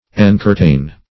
Encurtain \En*cur"tain\, v. t. To inclose with curtains.